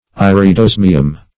Search Result for " iridosmium" : The Collaborative International Dictionary of English v.0.48: Iridosmine \Ir`i*dos"mine\, Iridosmium \Ir`i*dos"mi*um\, n. [Iridium + osmium.]